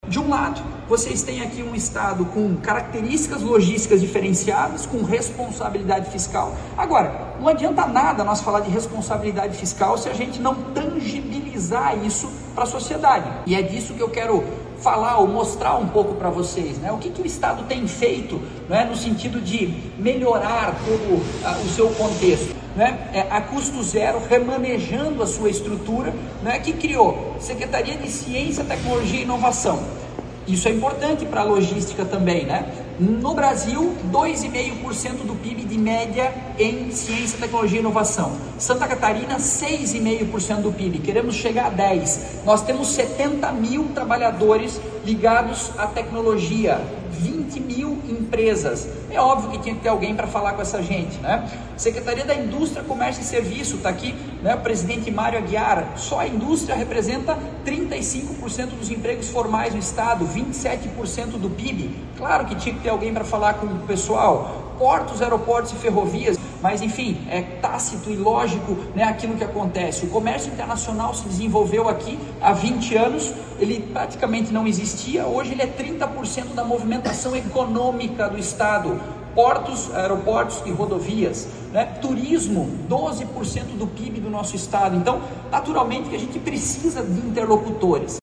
Este foi um dos principais recados do secretário da Fazenda, Cleverson Siewert, na palestra que abriu a programação do Logistique Summit 2024 nesta terça-feira, 23, em Balneário Camboriú.
Siewert falou sobre o desenvolvimento econômico e as perspectivas para o futuro de SC: